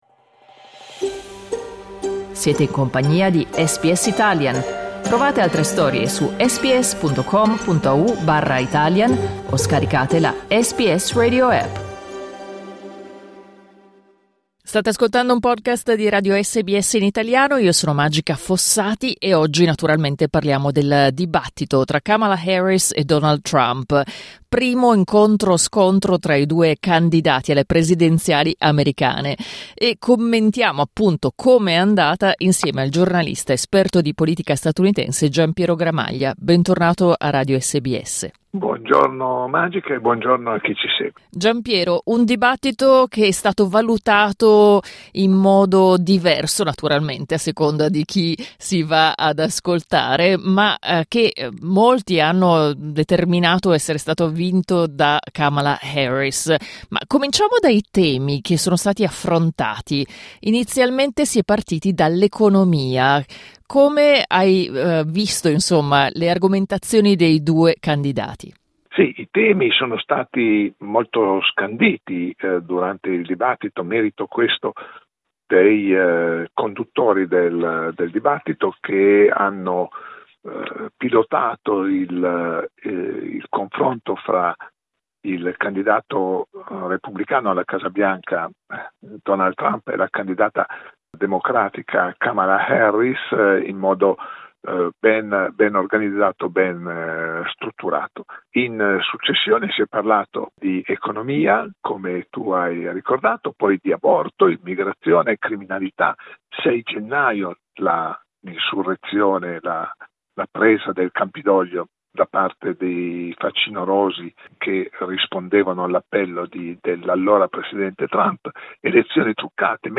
ci siamo collegati con il giornalista esperto di politica statunitense